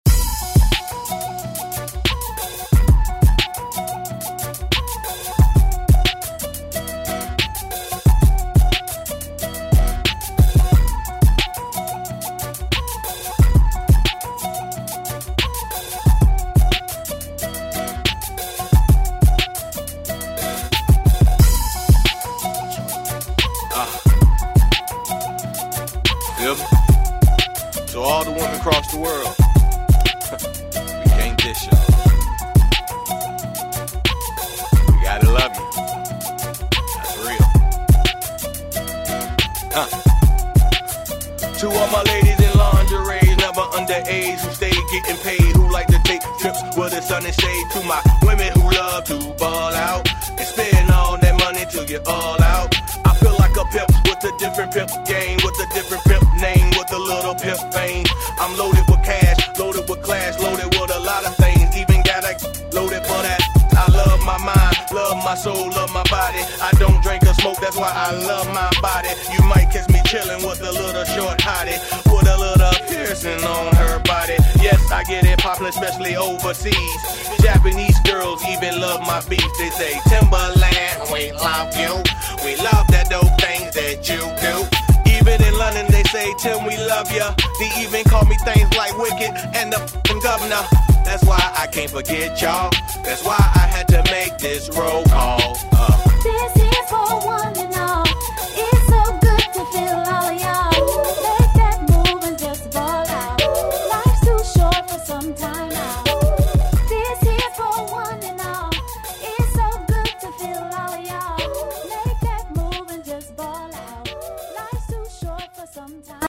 Dirty 90 bpm